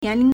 年龄 (年齡) niánlíng
nian2ling2.mp3